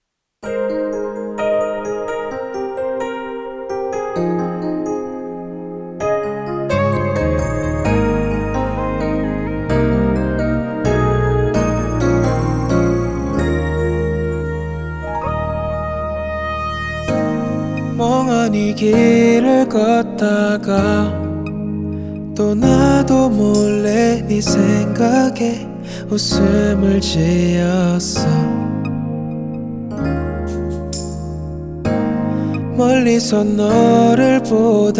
K-Pop Pop
2022-04-04 Жанр: Поп музыка Длительность